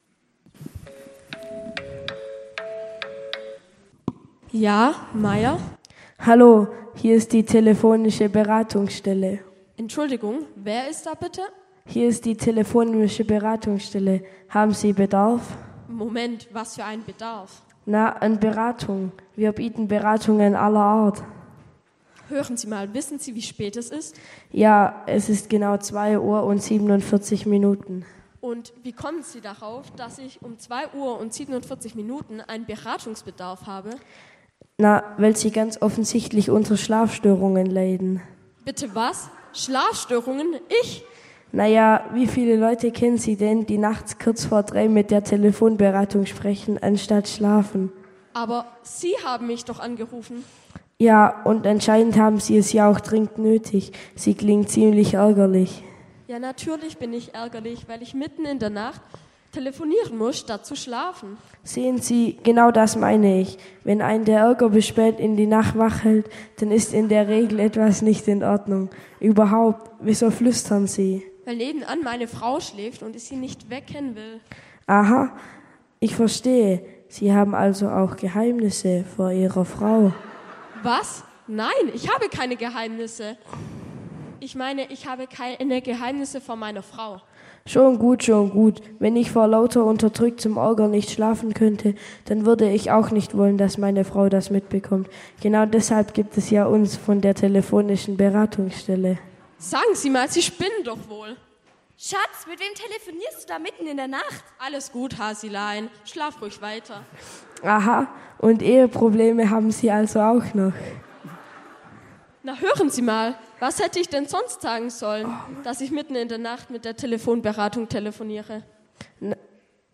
Predigt
mit Anspiel der Konfirmanden im Diakonie-Gottesdienst